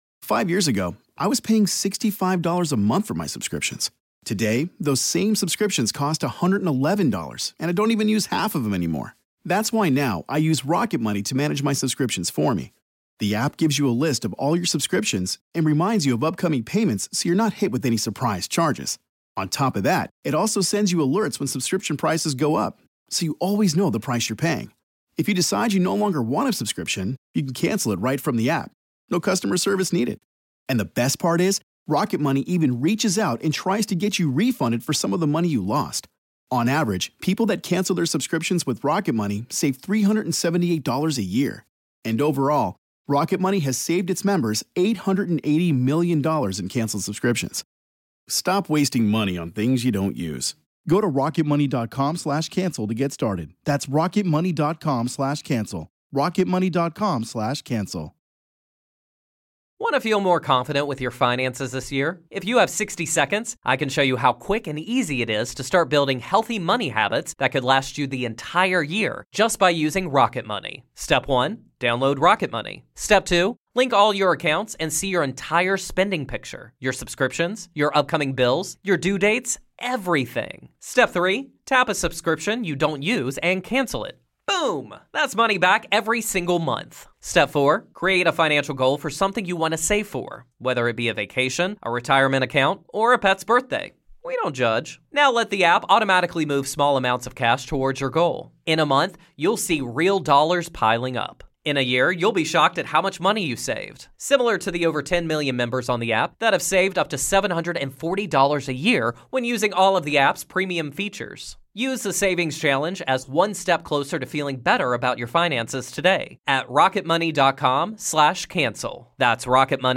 Exclusive Interview with Everclear's Art Alexakis